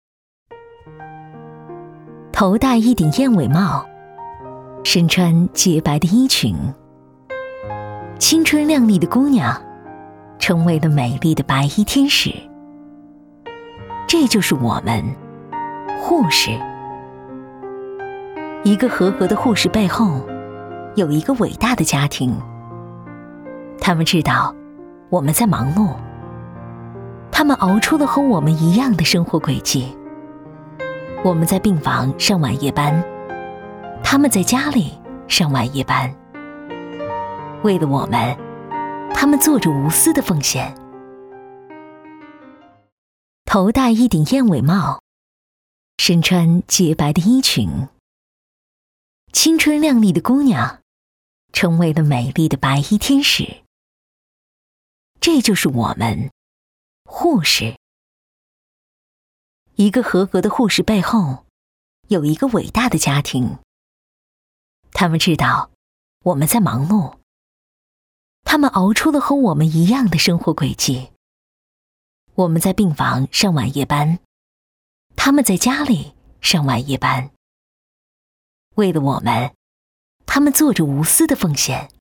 专注高端配音，拒绝ai合成声音，高端真人配音认准传音配音
女14